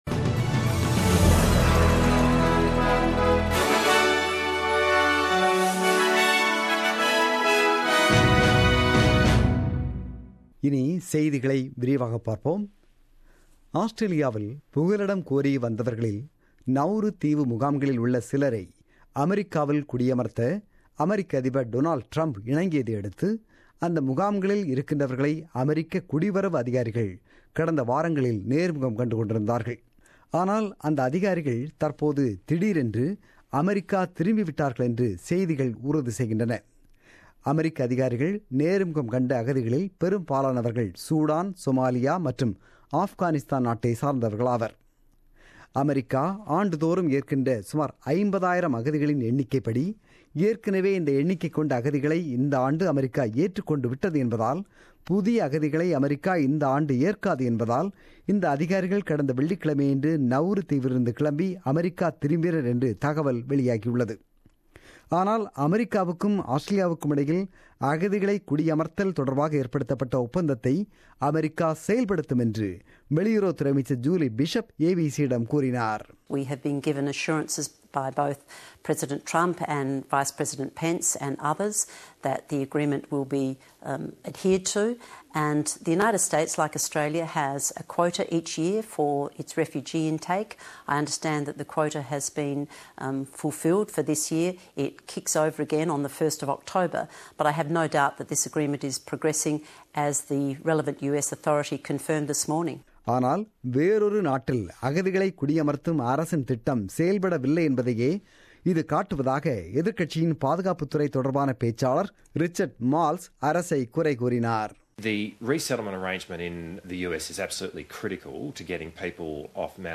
The news bulletin broadcasted on 16 July 2017 at 8pm.